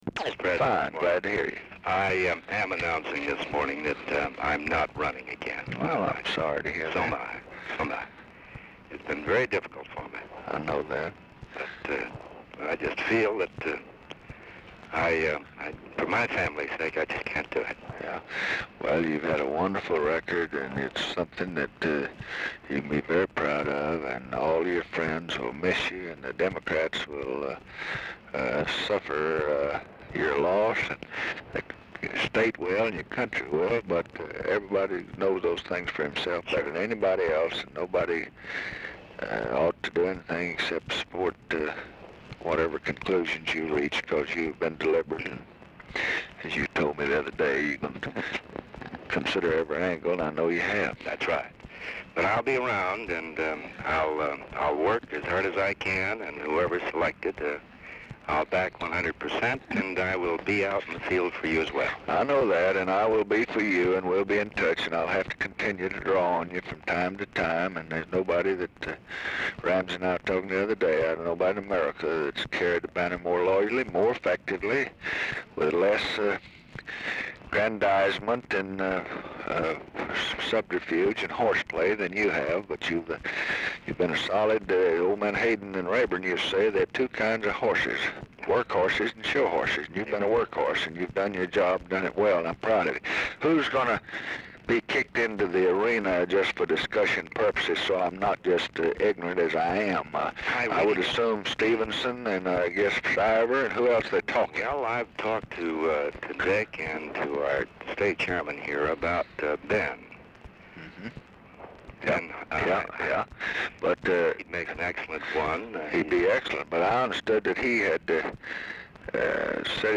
Telephone conversation # 12708, sound recording, LBJ and OTTO KERNER, 2/7/1968, 10:15AM | Discover LBJ
Format Dictation belt
Location Of Speaker 1 Mansion, White House, Washington, DC
Specific Item Type Telephone conversation